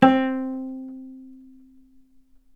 vc_pz-C4-ff.AIF